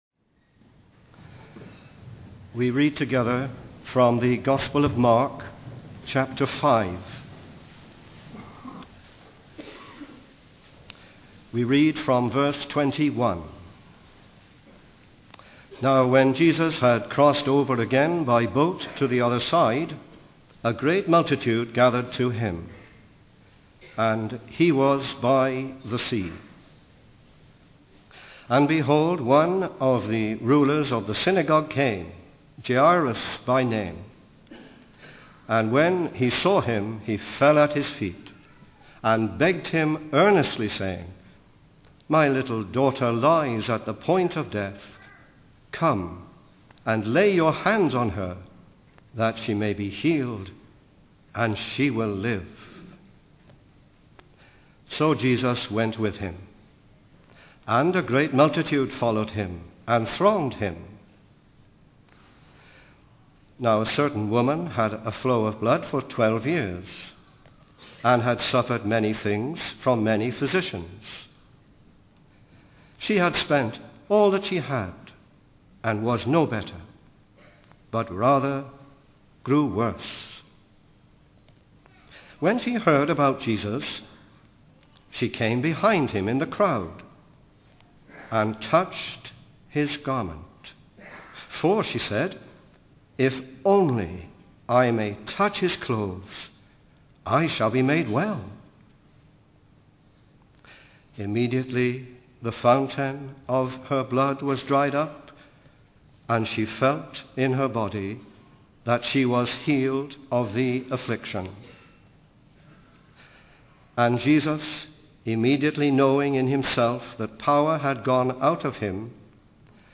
In this sermon, the preacher reflects on his previous experience of preaching at the same conference 25 years ago.